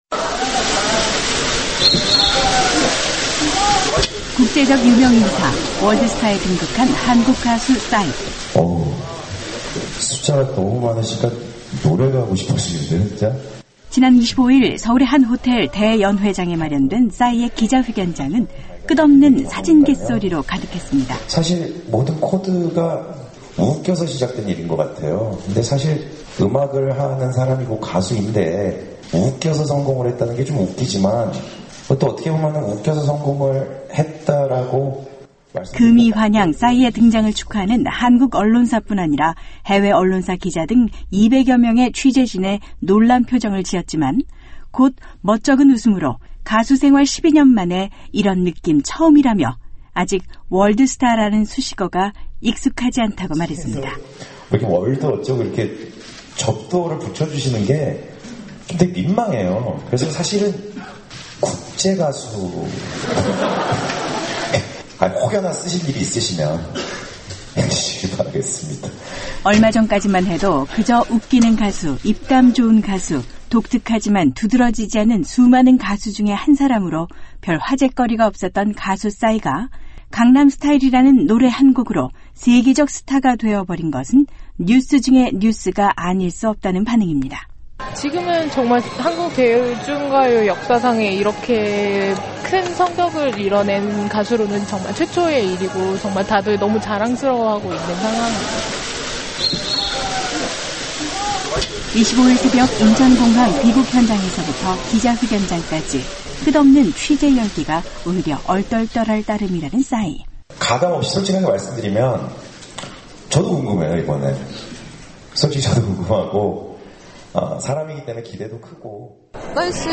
[안녕하세요, 서울입니다] '금의환향' 싸이 귀국 기자회견
‘안녕하세요 서울입니다’ 떠오르는 한류스타 가수 ‘싸이’의 기자회견장으로 가보겠습니다.